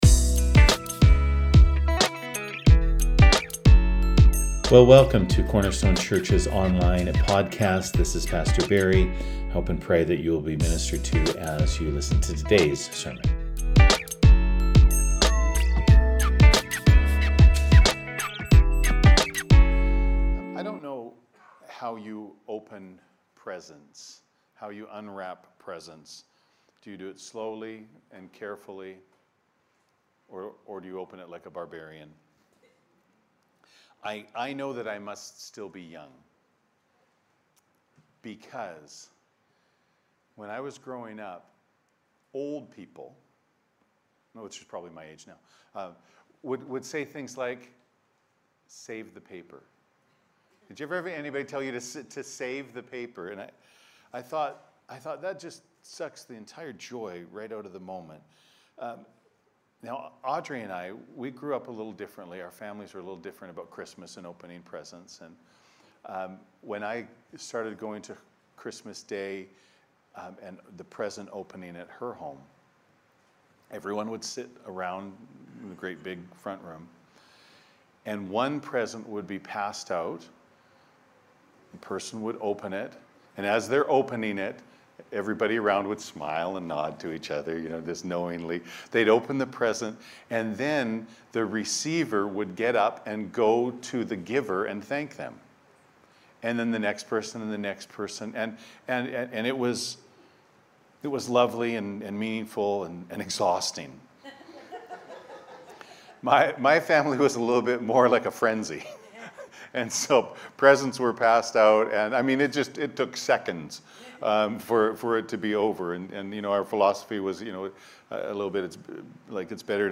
Sermons | Cornerstone Church